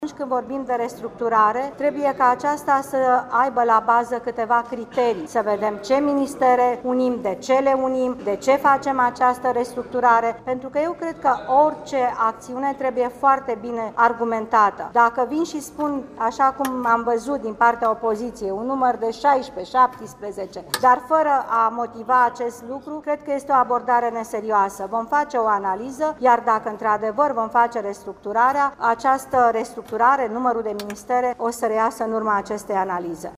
Premierul Viorica Dăncilă a declarat, astăzi, la Vaslui,  că dacă la toamnă va avea loc o restructurare a Guvernului, numărul de ministere se va stabili în urma unei analize bazate pe anumite criterii.